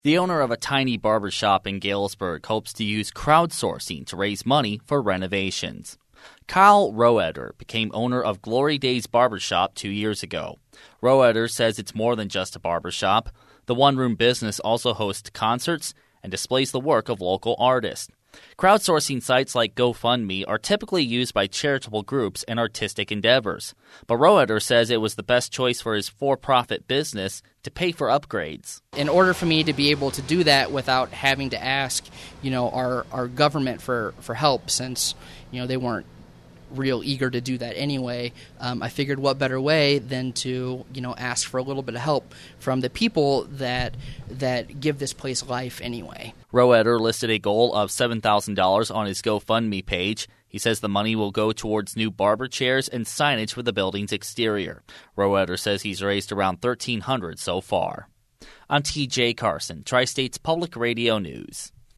The radio story.